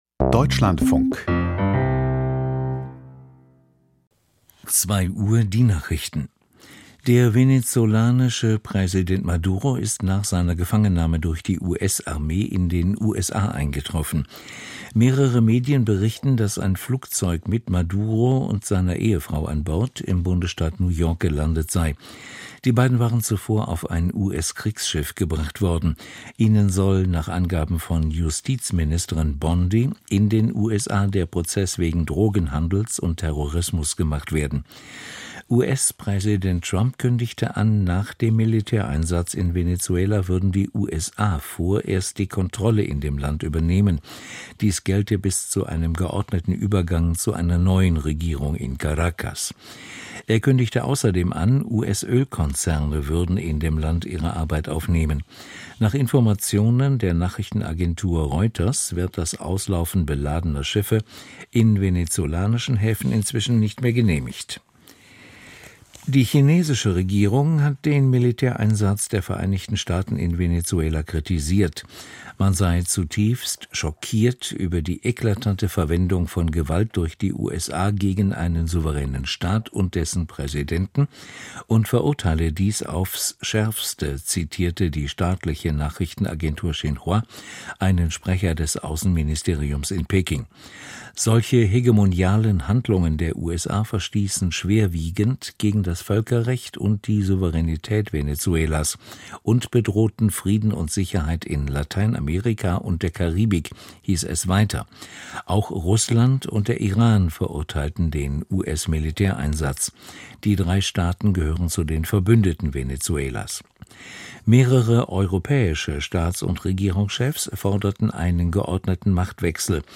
Die Nachrichten vom 04.01.2026, 02:00 Uhr
Aus der Deutschlandfunk-Nachrichtenredaktion.